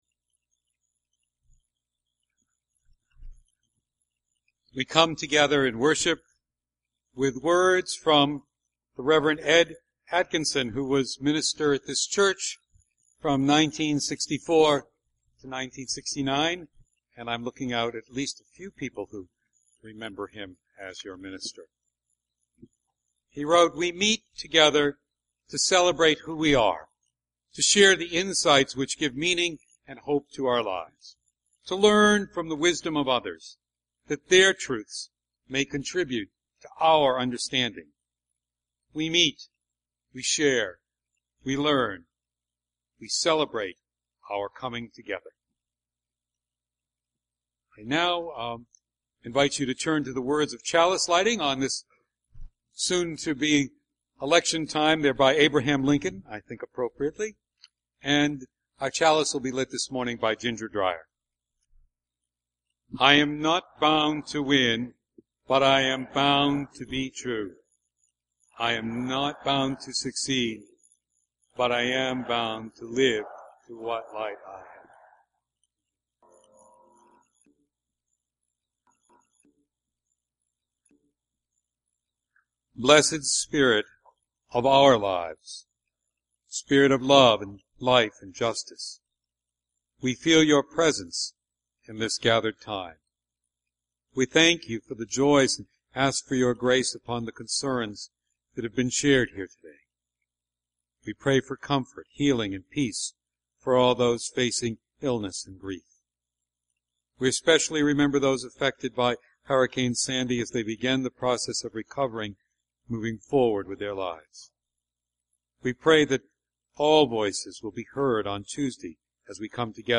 This morning we will explore this difficult issue. In our worship we will share joys and concerns and rich music making.